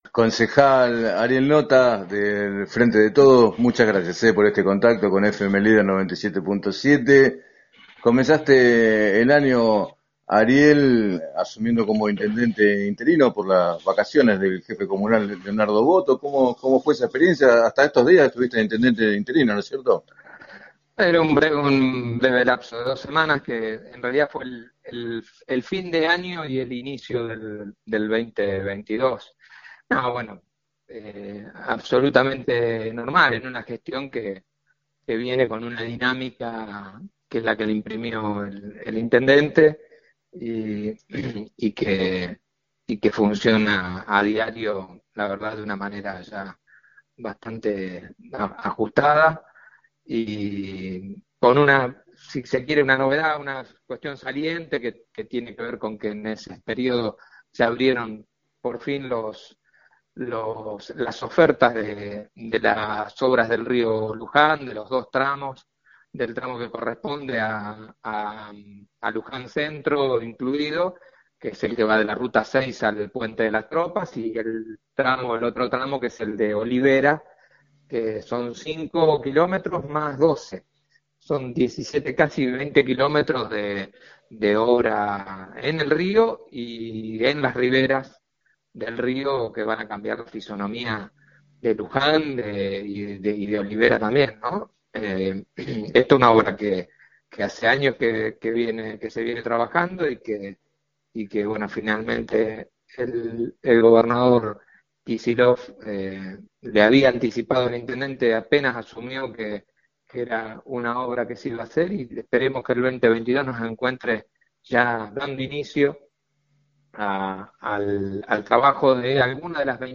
En declaraciones al programa “7 a 9” de FM Líder 97.7, Ariel Notta, concejal del Frente de Todos, afirmó que ya trabaja para la reelección del presidente Alberto Fernández y expresó que en 2023 en Luján debería haber competencia al interior del Frente de Todos en las elecciones primarias.